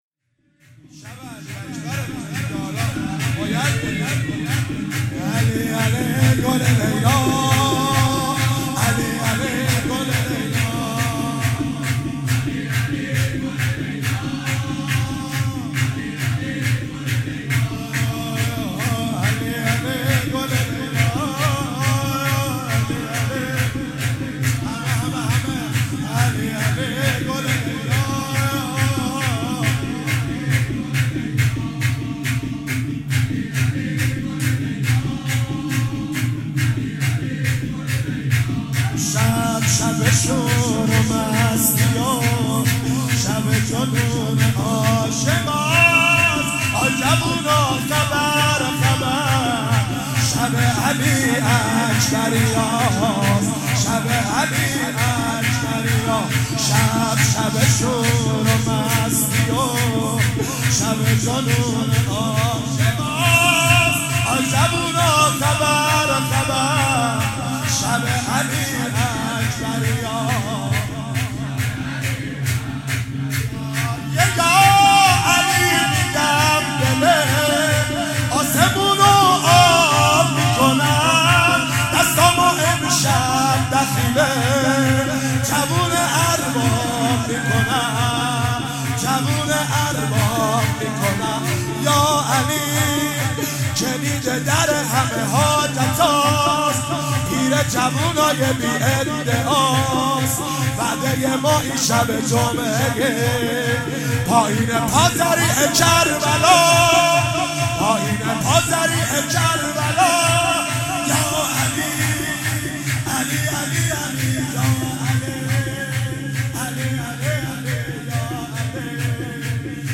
شور مولودی